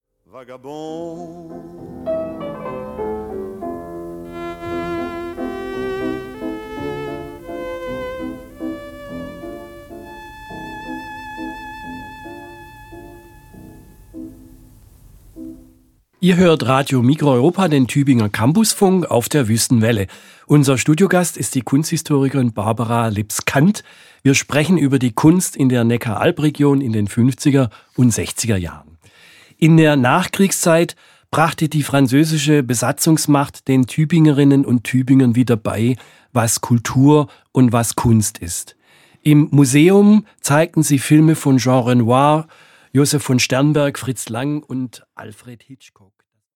Kunst nach 1945 - Studiogespräch